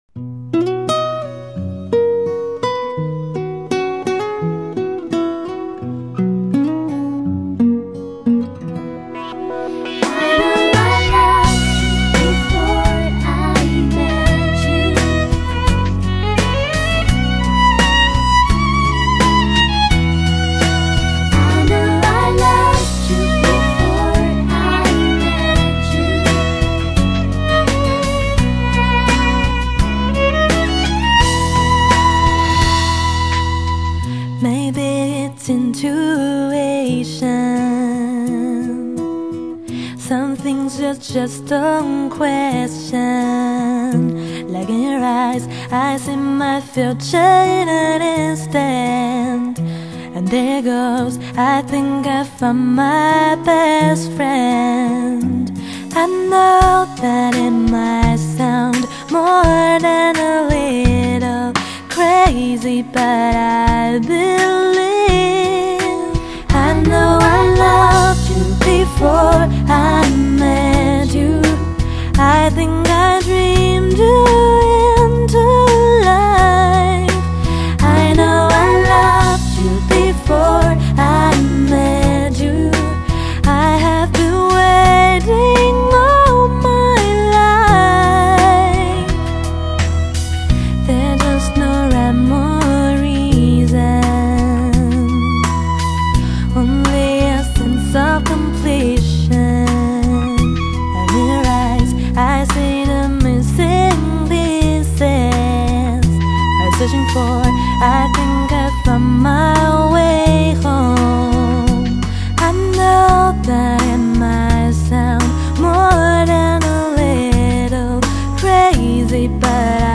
温暖感性  完美无瑕  动人的情怀直抵灵魂深处  超靓的录音
深阔无边的音场表现力  超高频的穿越空间感令人惊奇